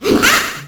snd_joker_ha1.ogg